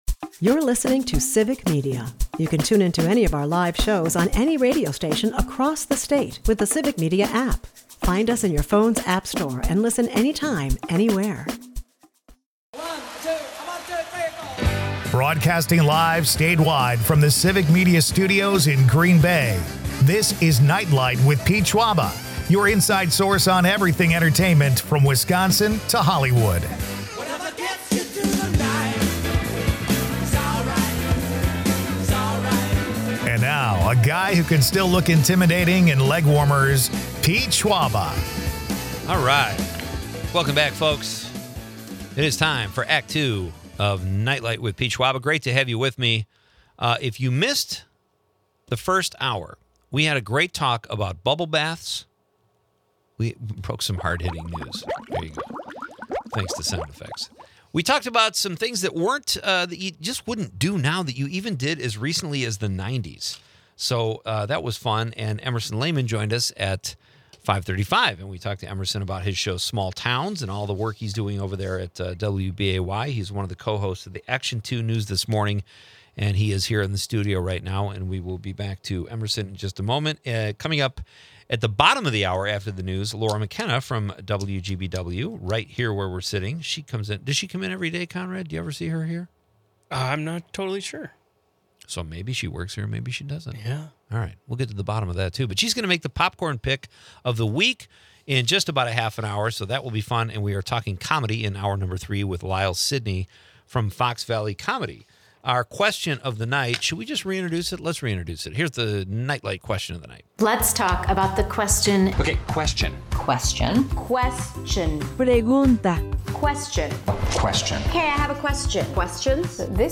humorous sound effects galore